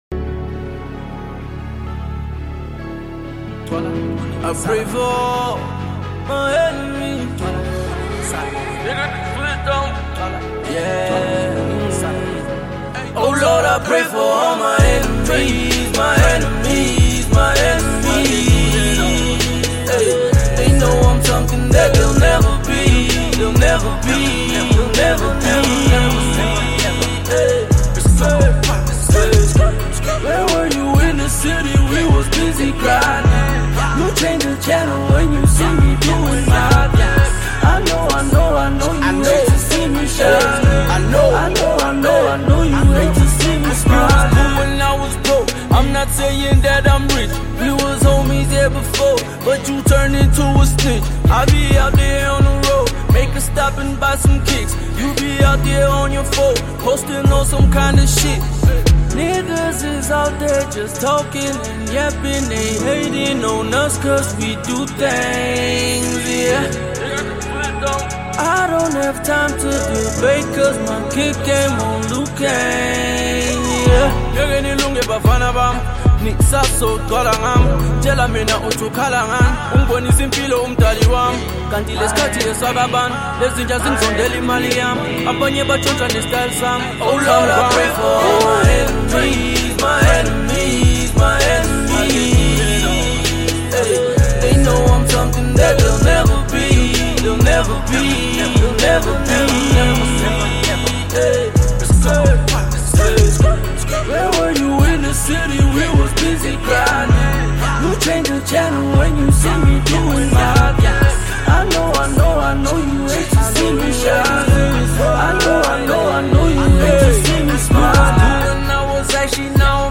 South African hip-hop rapper
trap hit